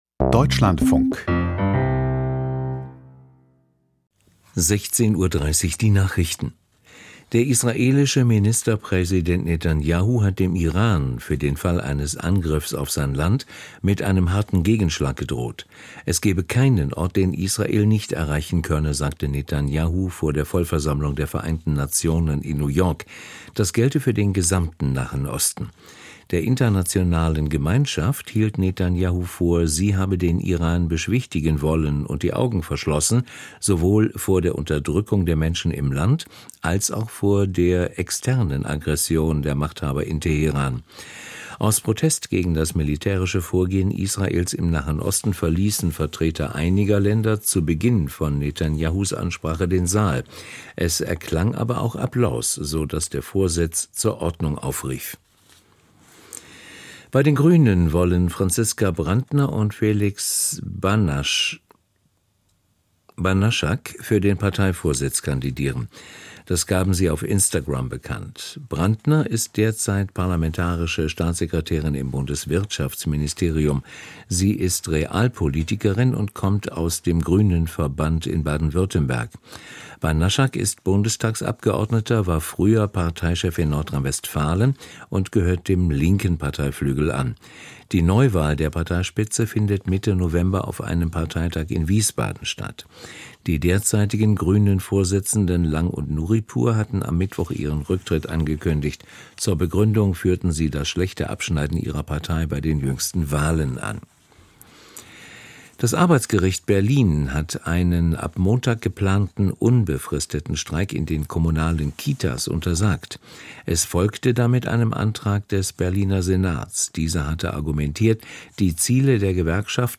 Die Deutschlandfunk-Nachrichten vom 27.09.2024, 16:30 Uhr